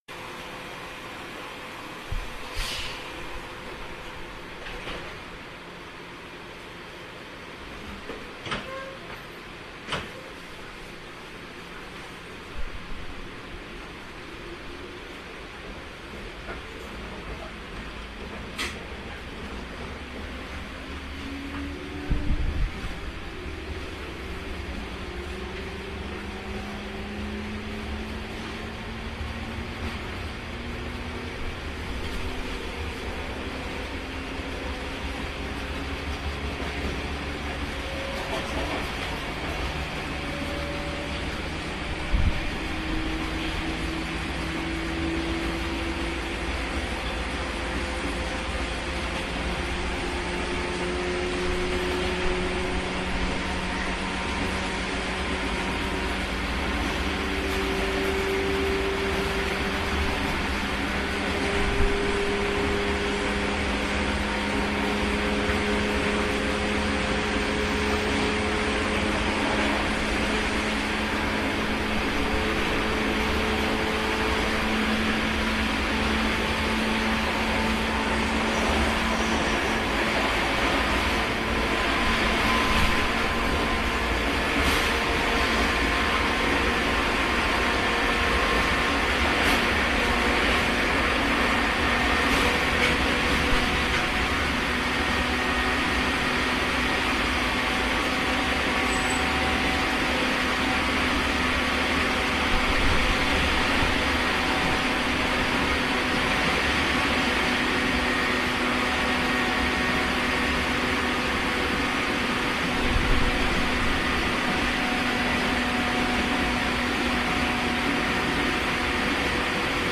まずは上の常磐線と同じく、低音のモーターです。例によって最高速で爆走しています。